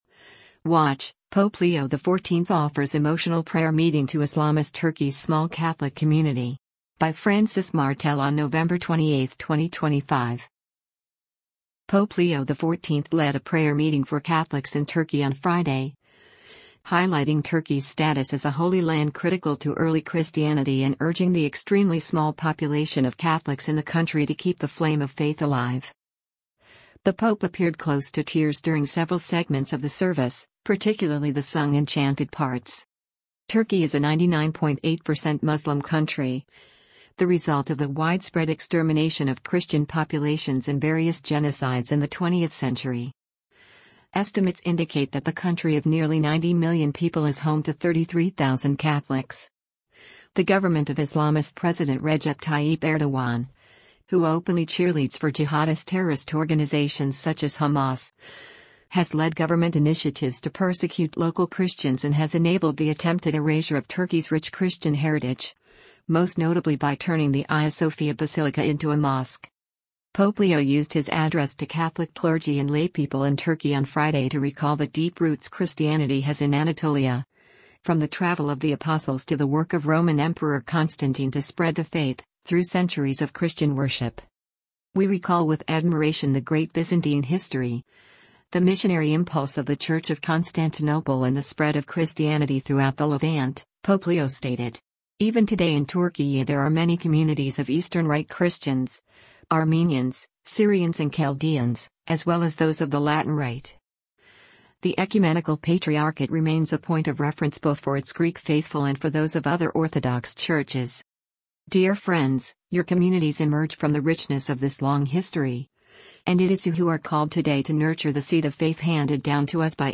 Pope Leo XIV led a prayer meeting for Catholics in Turkey on Friday, highlighting Turkey’s status as a “holy land” critical to early Christianity and urging the extremely small population of Catholics in the country to keep the flame of faith alive.
The pope appeared close to tears during several segments of the service, particularly the sung and chanted parts.